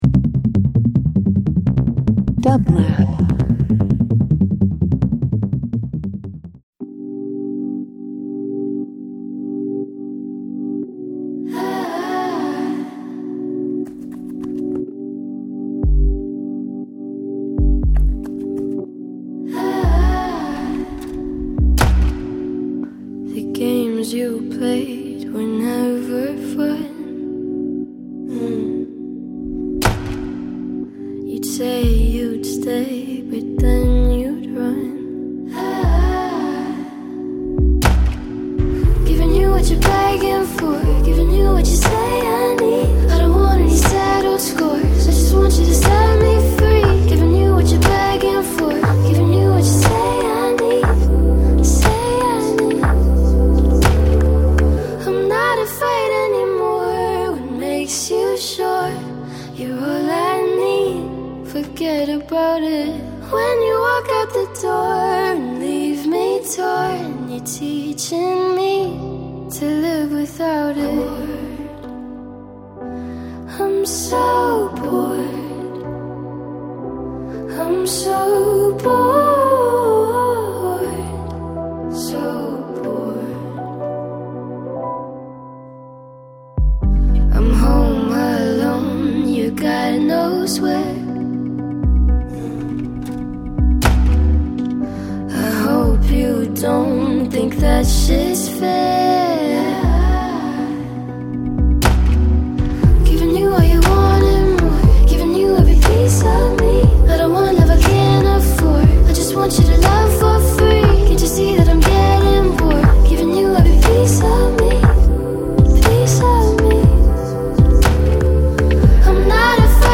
Studio Soundtracks takes listeners behind the scenes of how music is crafted for film and television by hearing directly from composers, songwriters and music professionals in the Entertainment Industry. Listen to inspiring conversations about composition and hear works from Emmy, Grammy, and Oscar-winning film scores on the show.